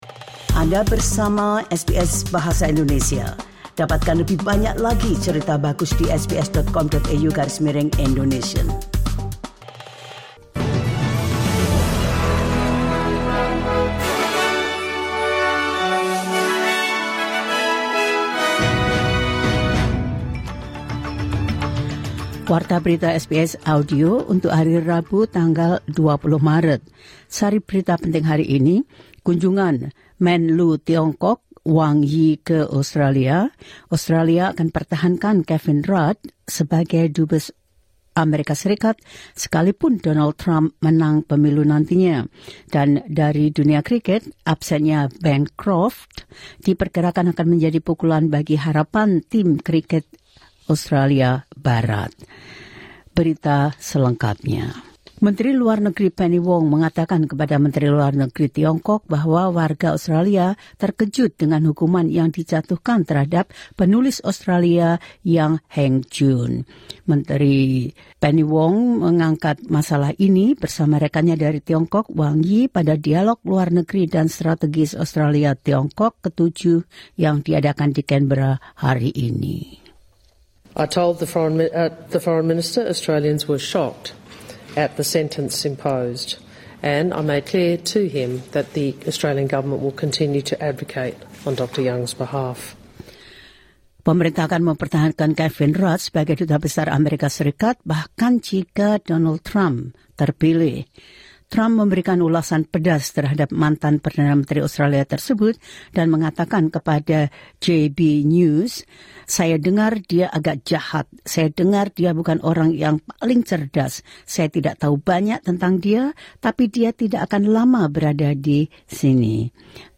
The latest news of SBS Audio Indonesian program – 20 Mar 2024